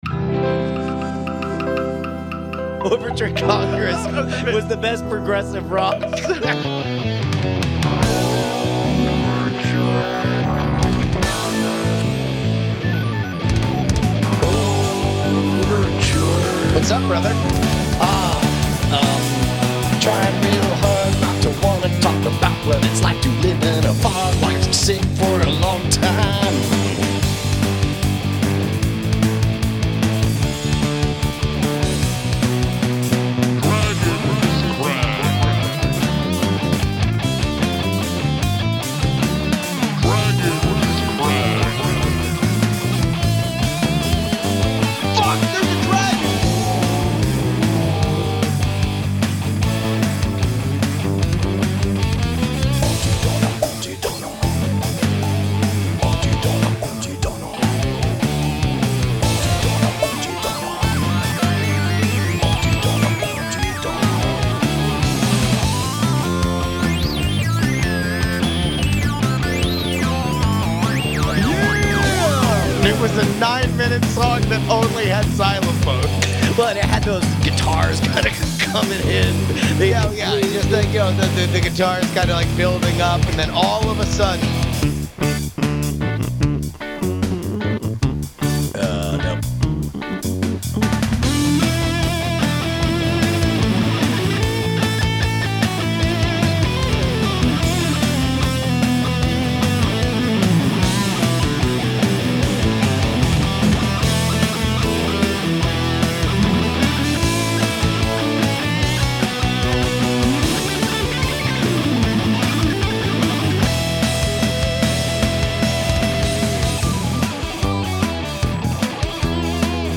math rock